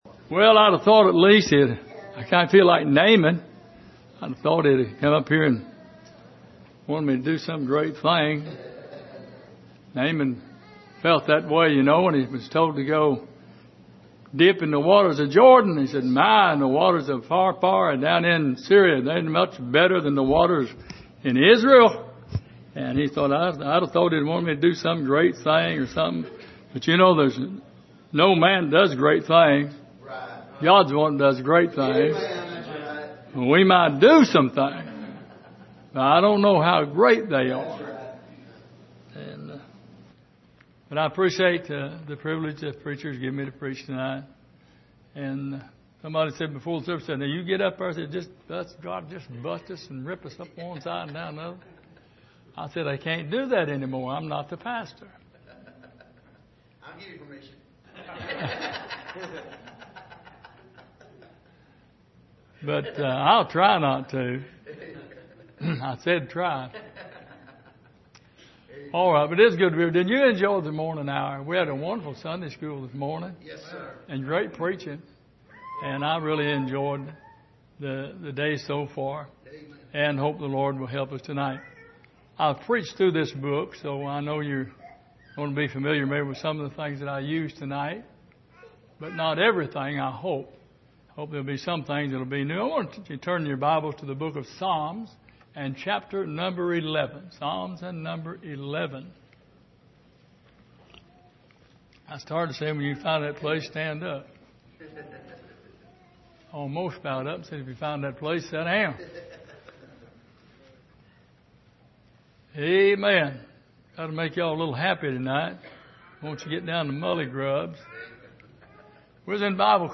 Passage: Psalm 11:1-7 Service: Sunday Evening